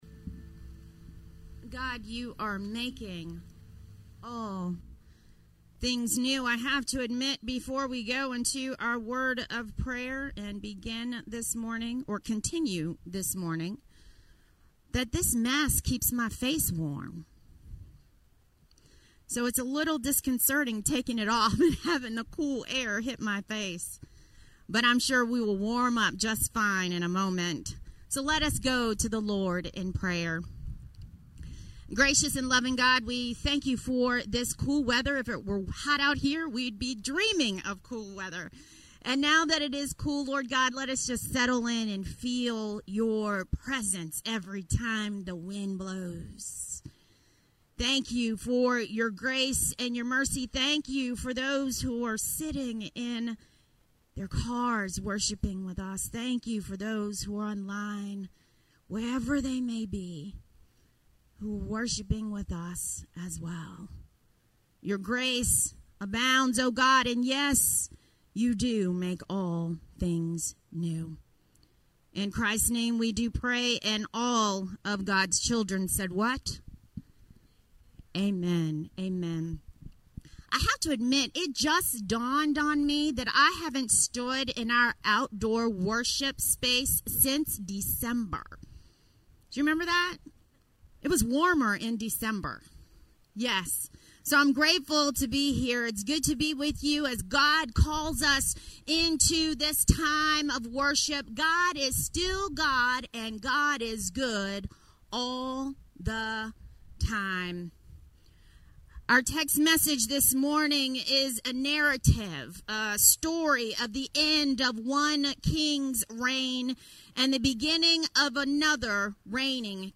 Sermon-1-24.mp3